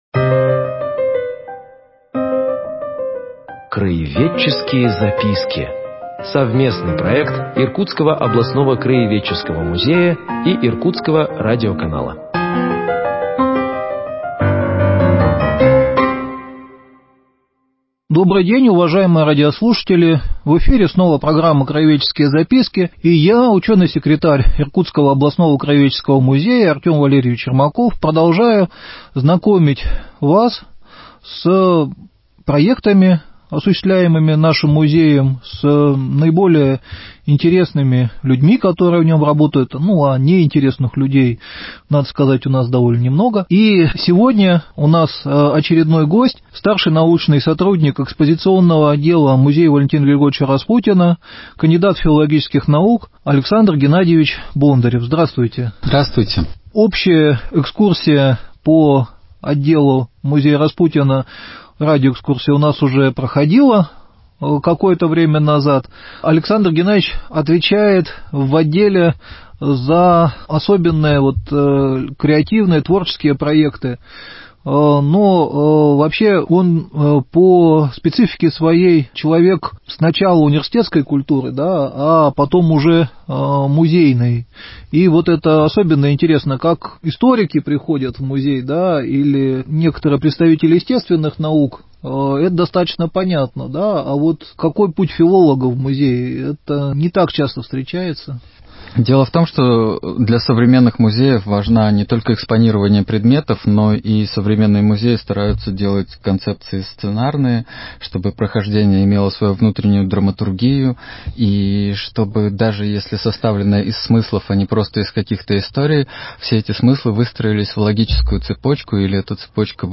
В этом выпуске он беседует